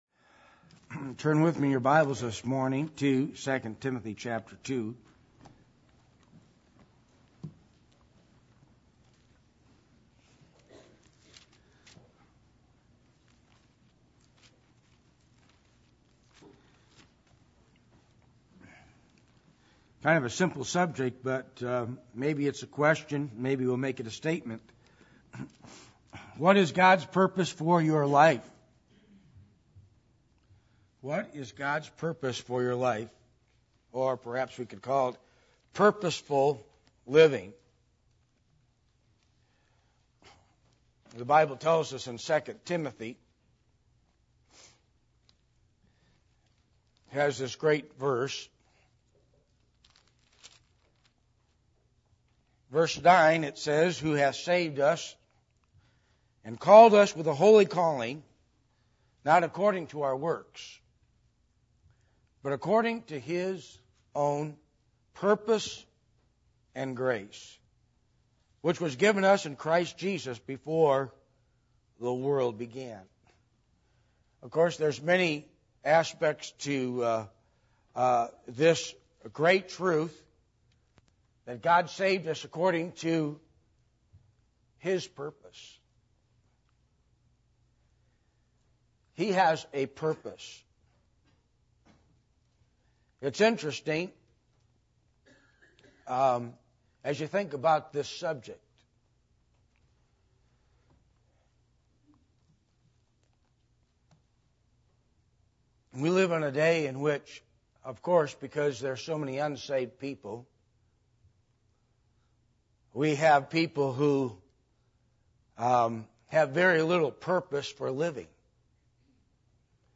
2 Timothy 1:19 Service Type: Sunday Morning %todo_render% « Biblical Principles Of Finances